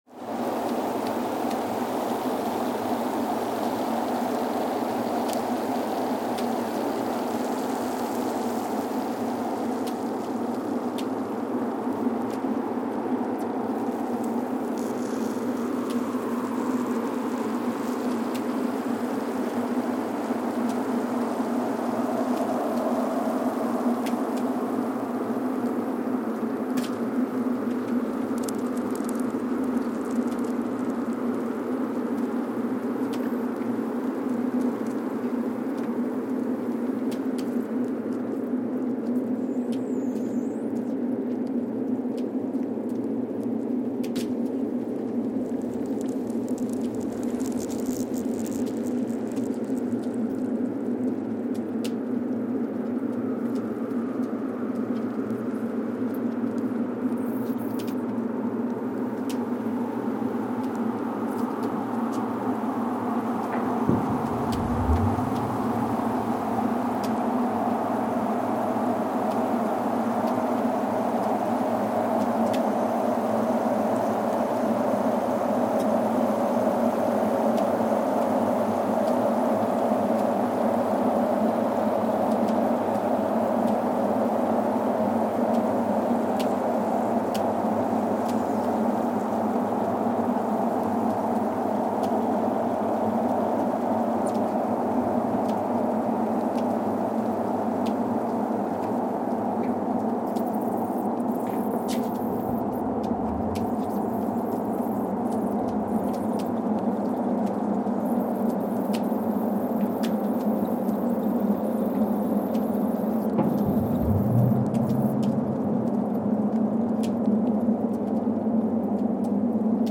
Casey, Antarctica (seismic) archived on September 16, 2020
Sensor : Streckheisen STS-1VBB
Speedup : ×1,800 (transposed up about 11 octaves)
Loop duration (audio) : 05:36 (stereo)
Gain correction : 25dB
SoX post-processing : highpass -2 90 highpass -2 90